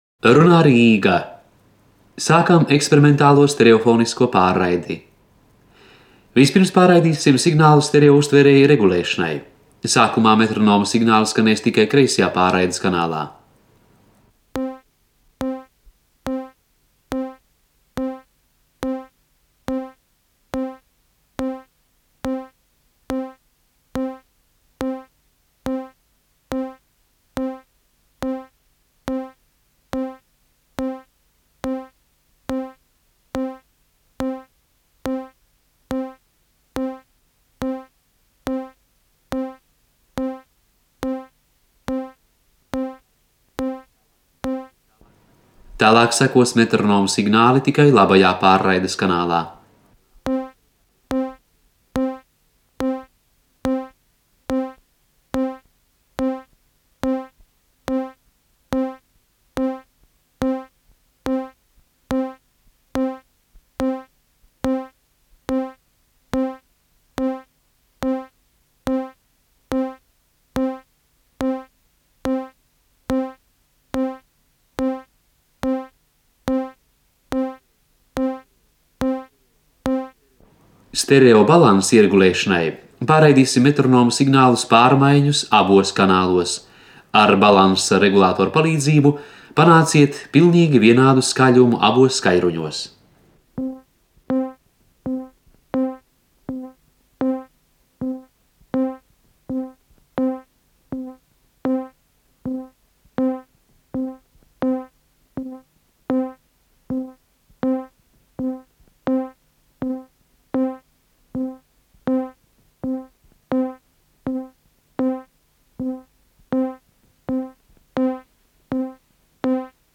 Stereotests kanālu noregulēšanai (1. variants)
Signāli un signalizācija
Trokšņu, efektu u.c. ieraksts